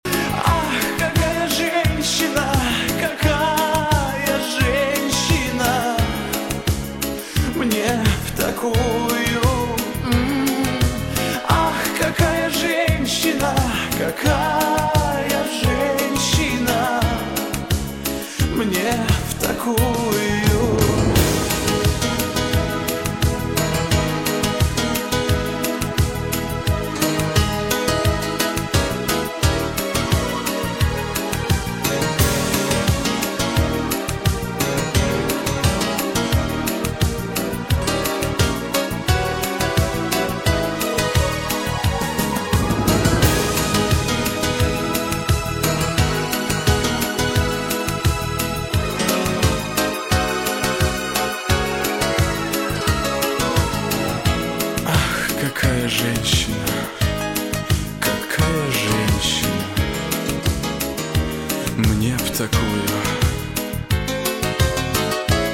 Жанр: Эстрада